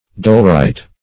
dolerite - definition of dolerite - synonyms, pronunciation, spelling from Free Dictionary
Dolerite \Dol"er*ite\, n. [Gr. dolero`s deceptive, because it